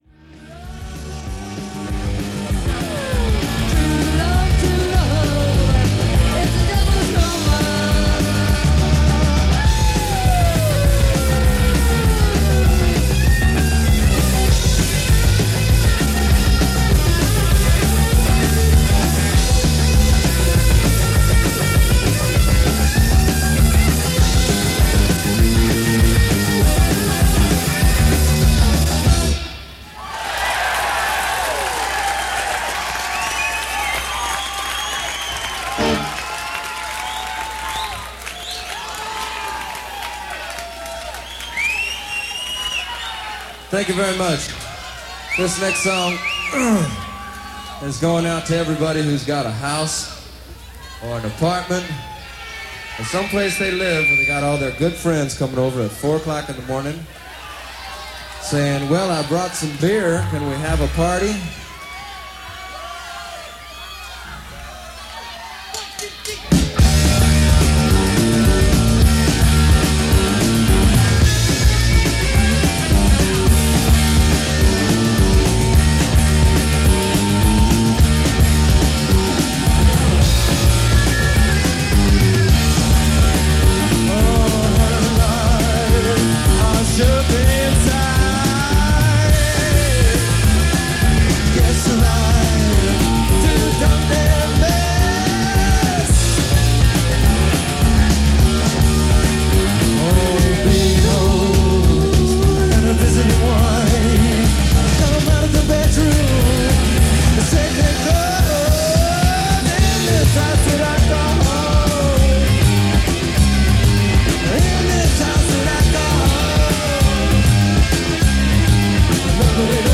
Broadcast concert.
Punk/New Wave/Rockabilly/Rootsrock